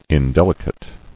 [in·del·i·cate]